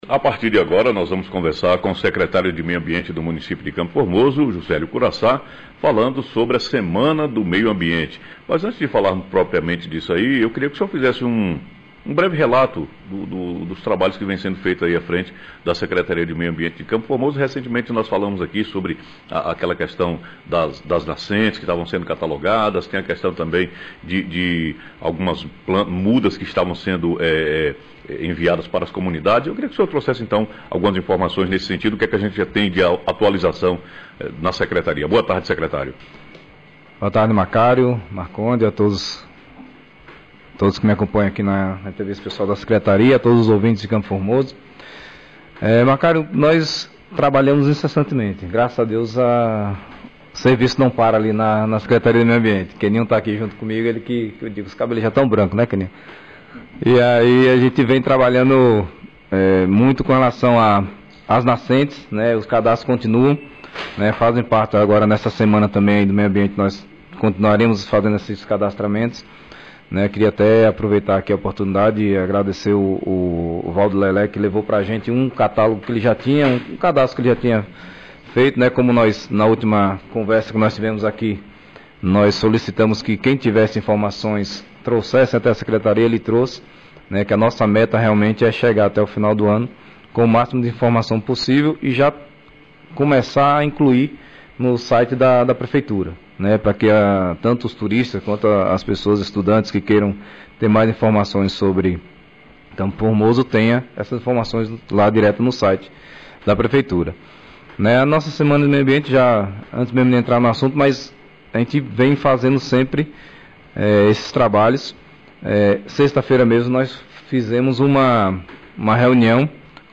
Entrevista secretário de meio ambiente Jucélio Curaçá